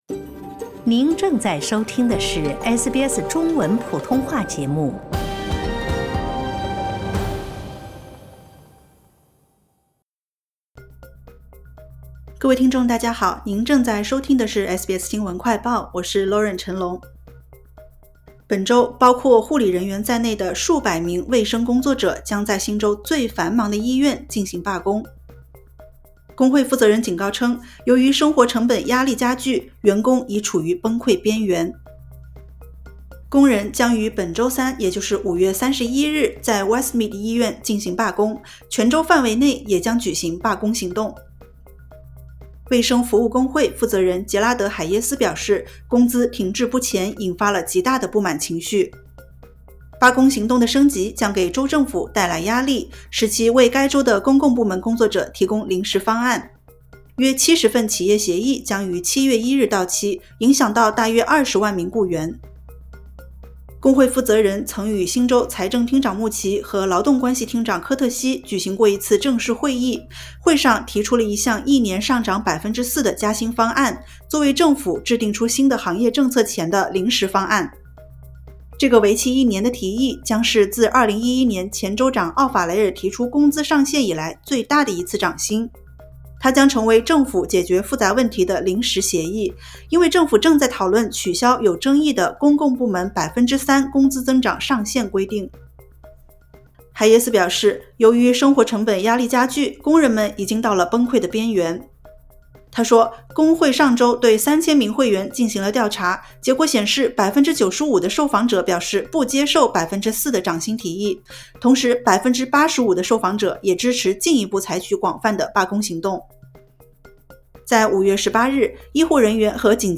【SBS新闻快报】卫生工作者将于Westmead医院罢工抗议工资问题